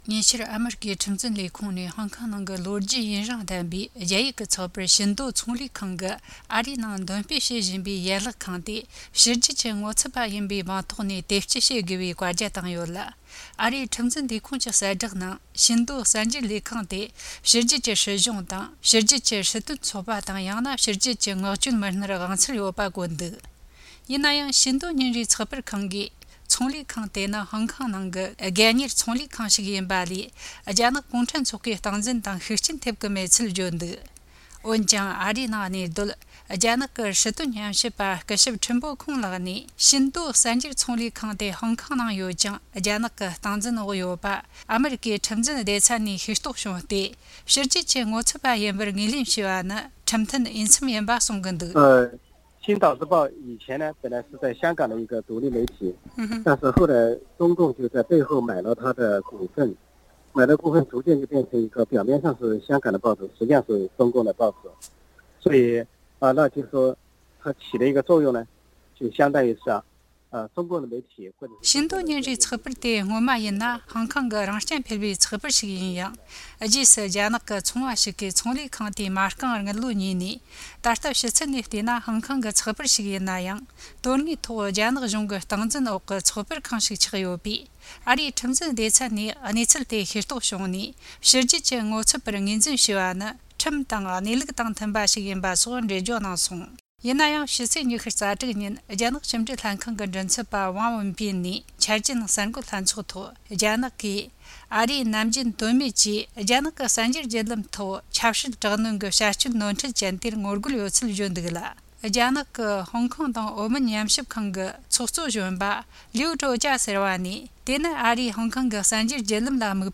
བཅར་འདྲི་དང་གནས་ཚུལ་ཕྱོགས་བསྡུས་བྱས་པར་གསན་རོགས་གནོངས།།